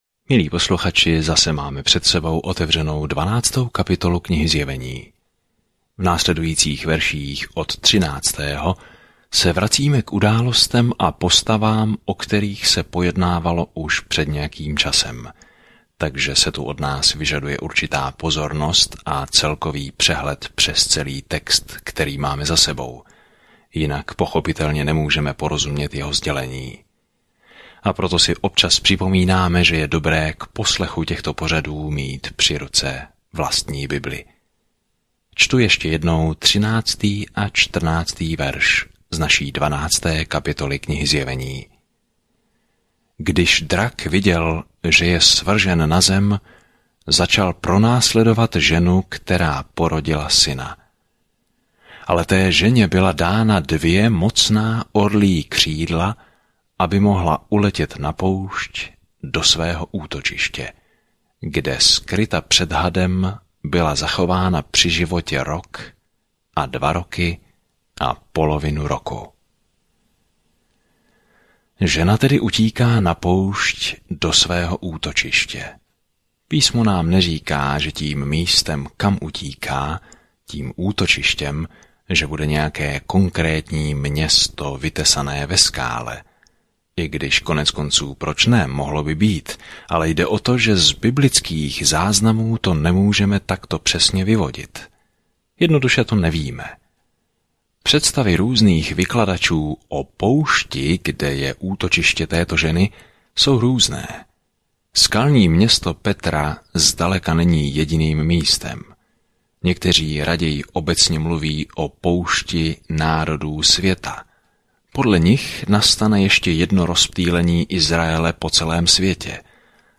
Písmo Zjevení 12:11-14 Den 37 Začít tento plán Den 39 O tomto plánu Zjevení zaznamenává konec rozsáhlé časové osy dějin s obrazem toho, jak bude se zlem konečně zacházeno a Pán Ježíš Kristus bude vládnout ve vší autoritě, moci, kráse a slávě. Denně procházejte Zjevení a poslouchejte audiostudii a čtěte vybrané verše z Božího slova.